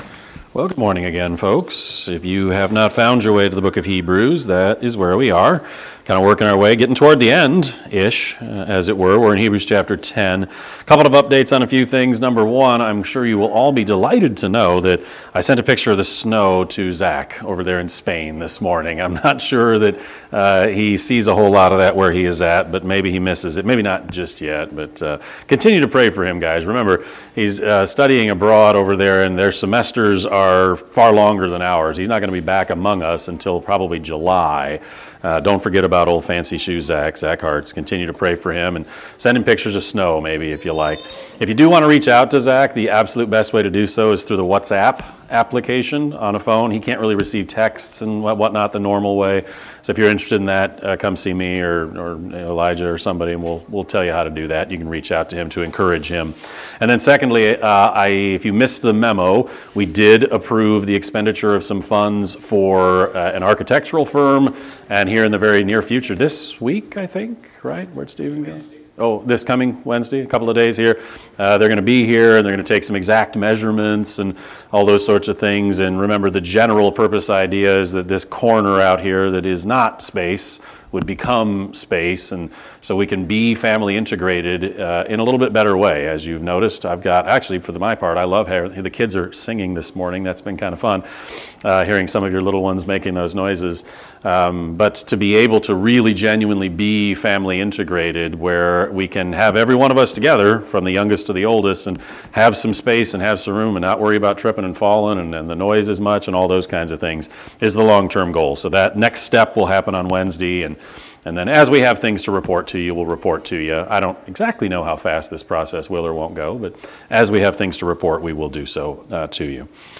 (Sorry for the poor audio quality.)
Sermon-11-9-25-Edit.mp3